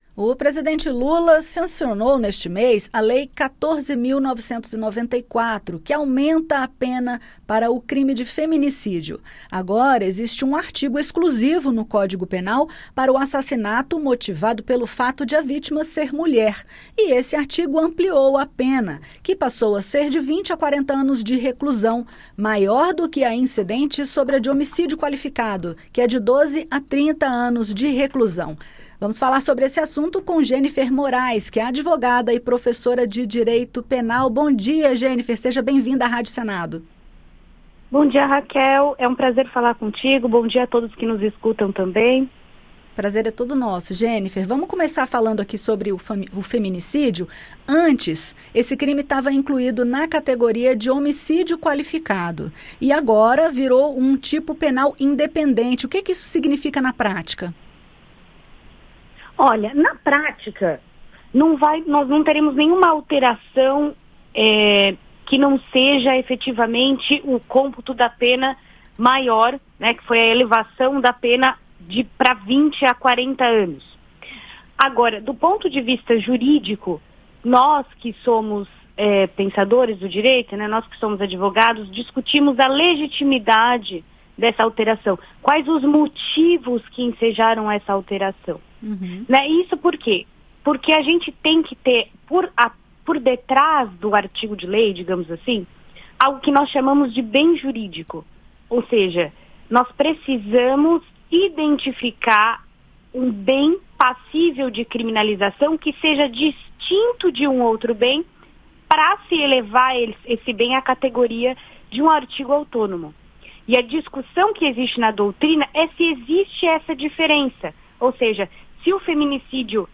Advogada comenta impactos da nova lei que aumenta a pena para o crime de feminicídio